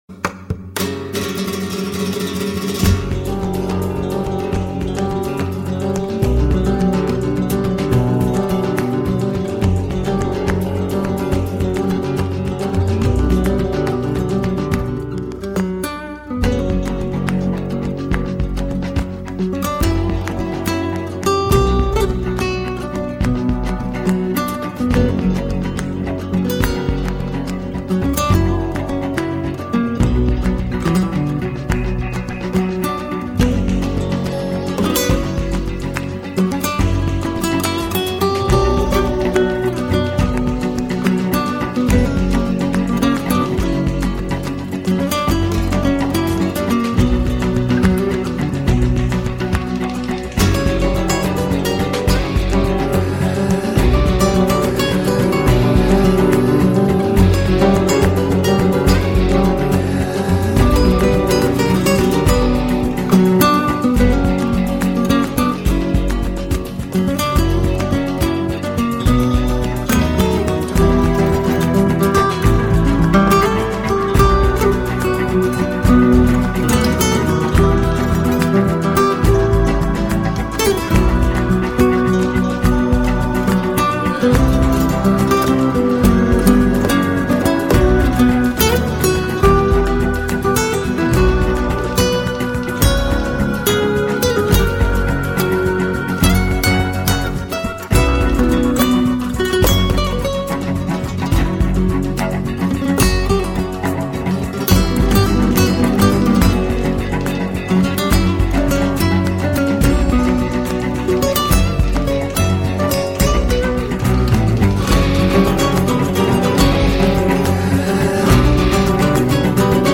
gitara_muzyka.mp3